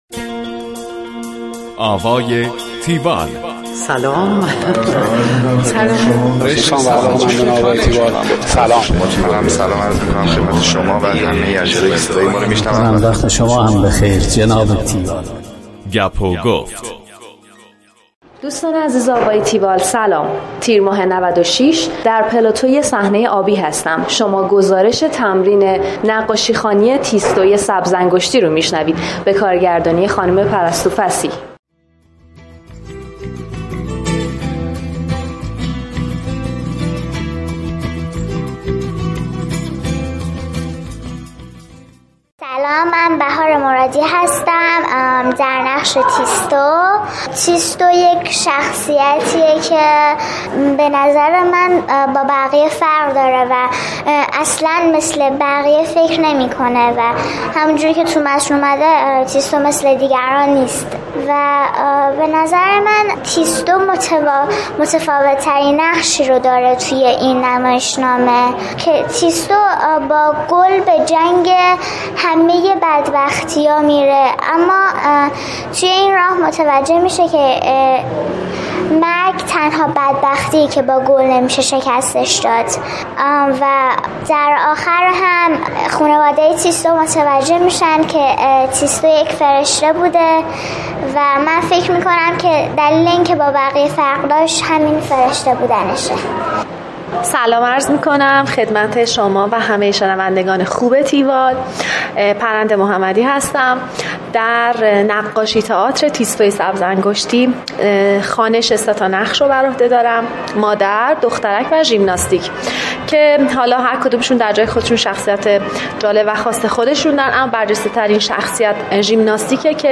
گزارش آوای تیوال از نقاشی تئاتر تیستوی سبزانگشتی